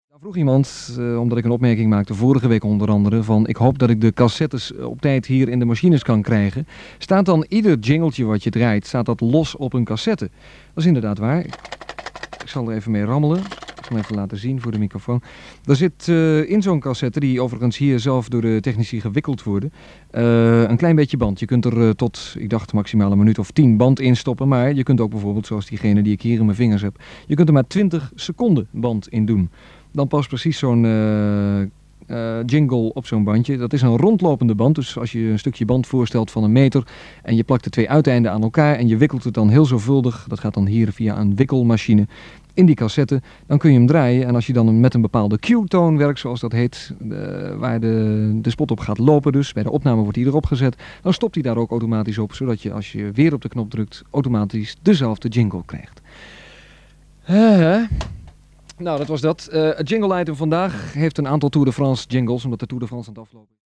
Zoals bekend zond DJ Ferry Maat in zijn Soulshow tussen 20 november 1975 en medio 1977 het jingle-item uit. In de aflevering van 21 juli 1977 beantwoord hij een vraag van een luisteraar: “Staat dan elke jingle die je draait op een aparte cassette?”. Ferry legt vervolgens uitgebreid het systeem van de jingle-cassettes en jingle-machines uit.